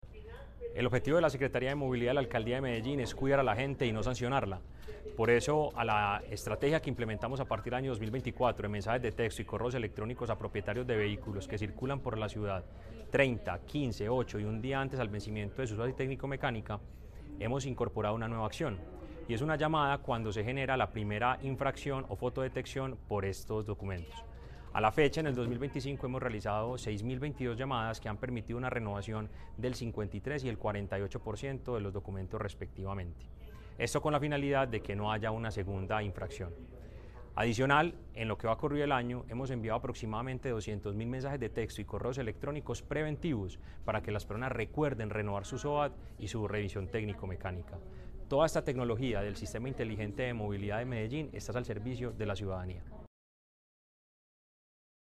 Palabras de Mateo González Benítez, secretario de Movilidad